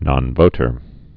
(nŏn-vōtər)